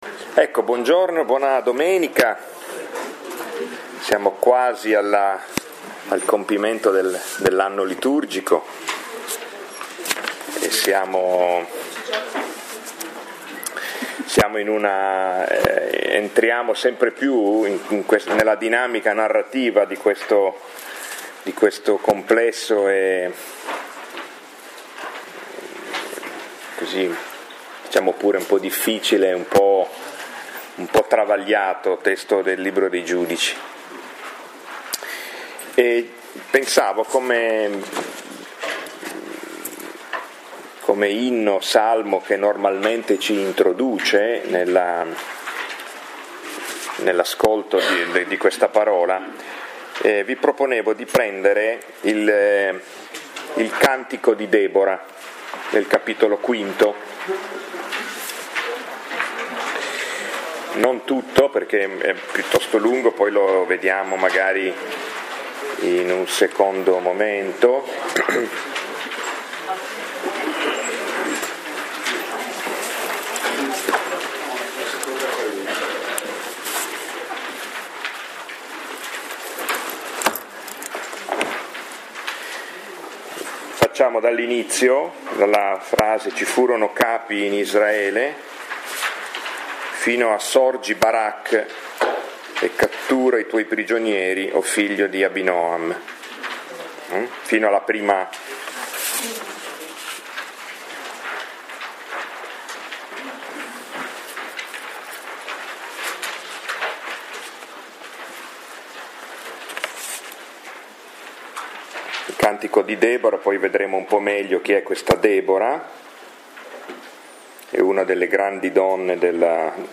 Lectio 2 – 19 novembre 2017